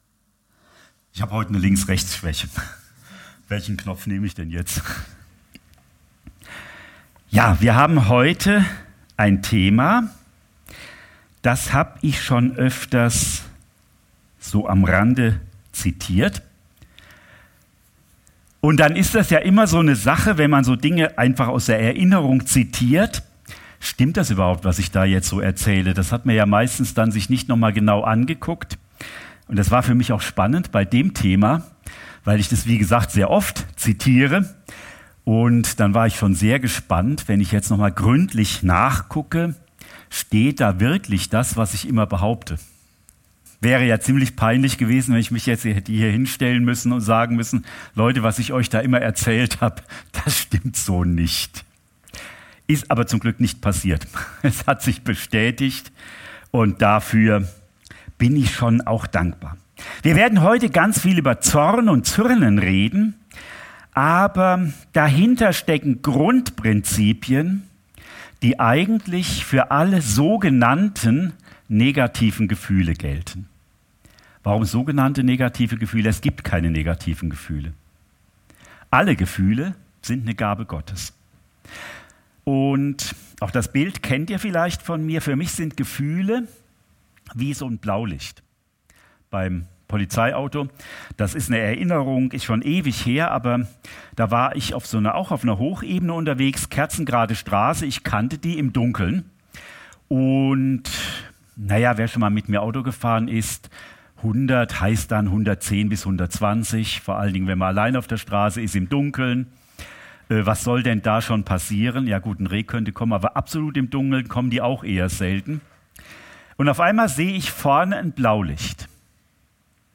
Predigt vom 18.